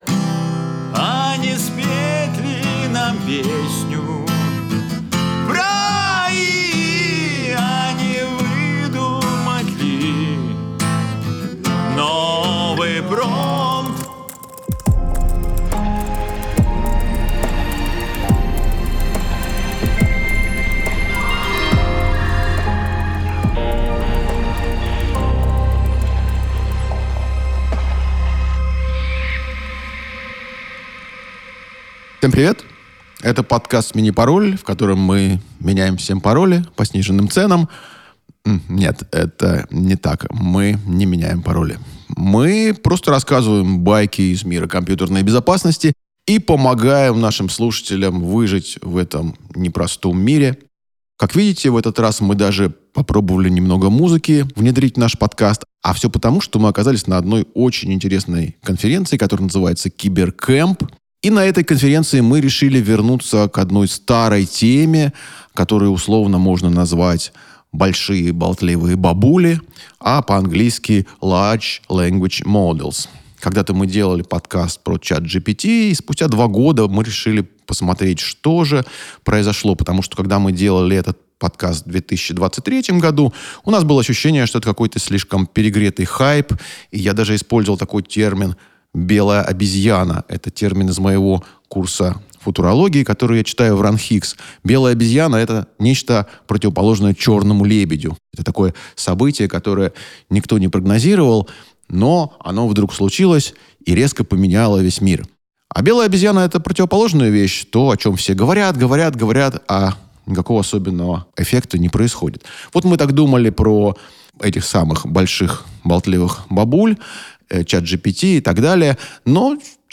Документально-разговорный подкаст о кибербезопасности.